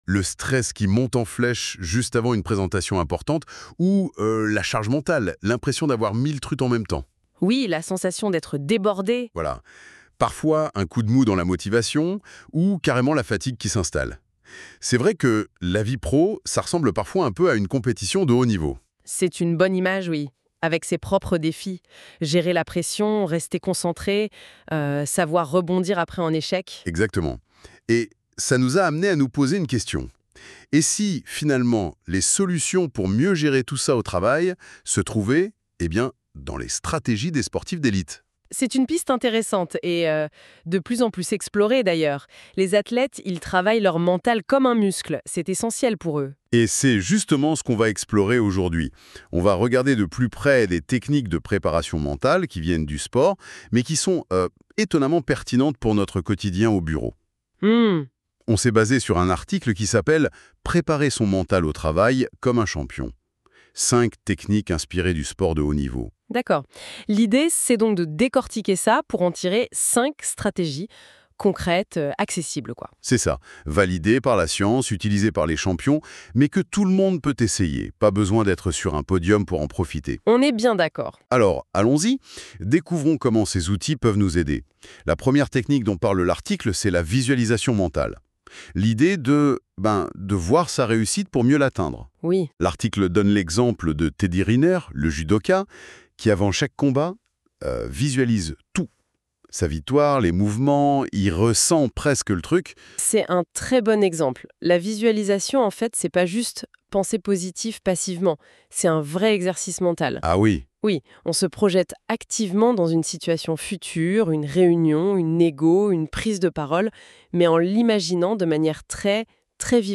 Entretien : La préparation mentale
Podcast généré à l'aide de l'IA NotebookLM